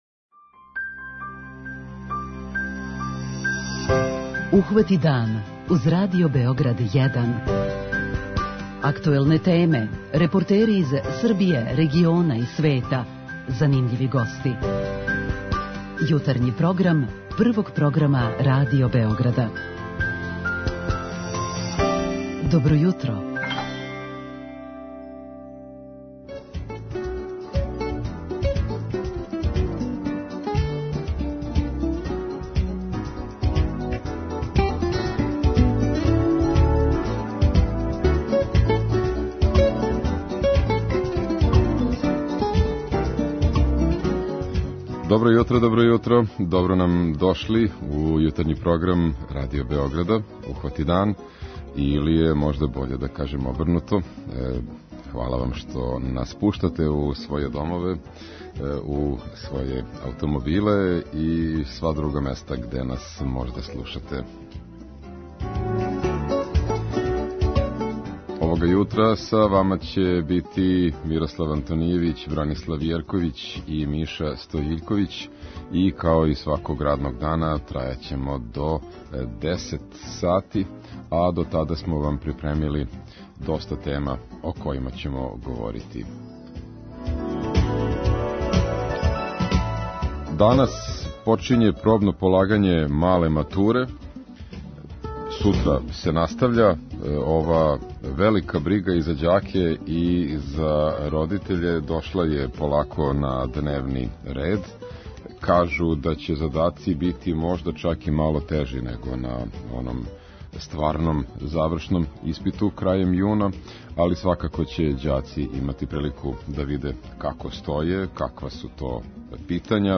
Група аутора Јутарњи програм Радио Београда 1!